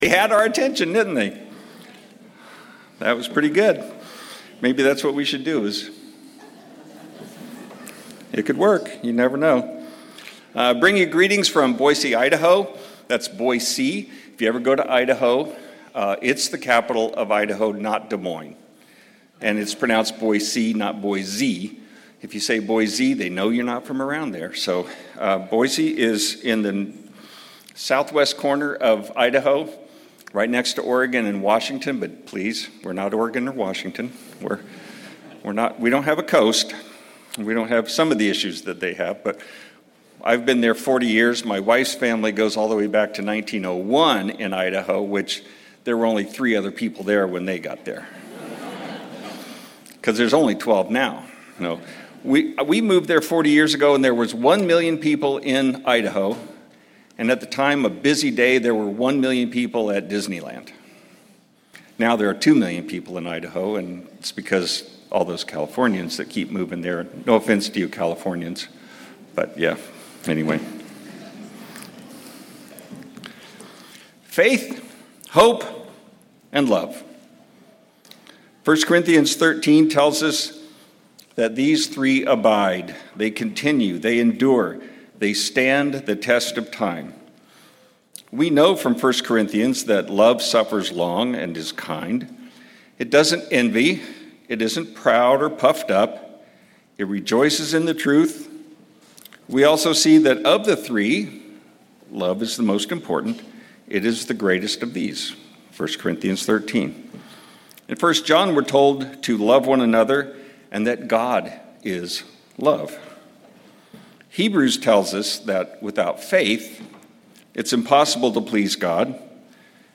Given in Morehead City, North Carolina
Feast of Tabernacles Sermon Studying the bible?